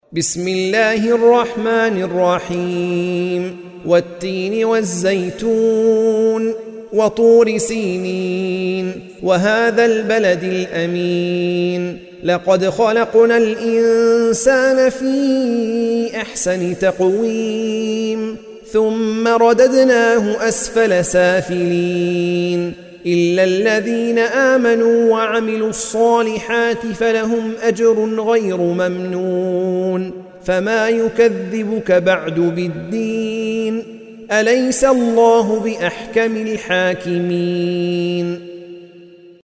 سورة التين مكية عدد الآيات:8 مكتوبة بخط عثماني كبير واضح من المصحف الشريف مع التفسير والتلاوة بصوت مشاهير القراء من موقع القرآن الكريم إسلام أون لاين